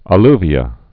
(ə-lvē-ə)